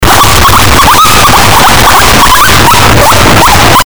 cuy-cuy-cuy-distorsionado_sCBMVxQ.mp3